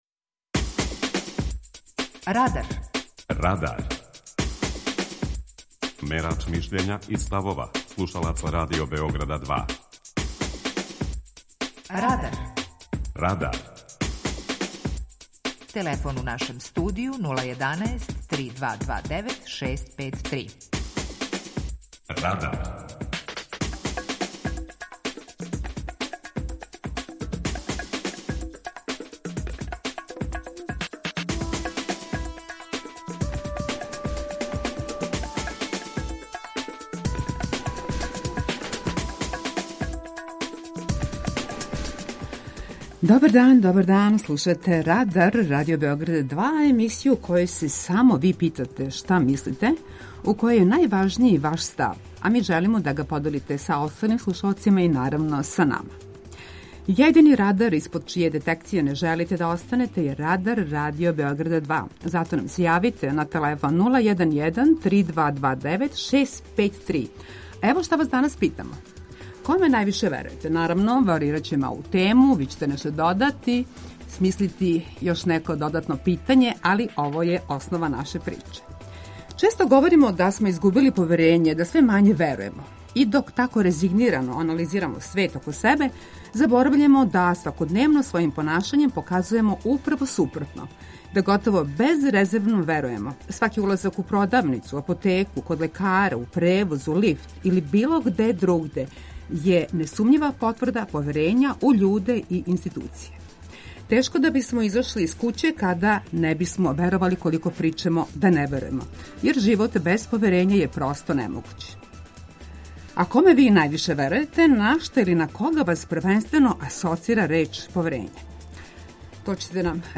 Питање Радара је: Коме највише верујете? преузми : 18.96 MB Радар Autor: Група аутора У емисији „Радар", гости и слушаоци разговарају о актуелним темама из друштвеног и културног живота.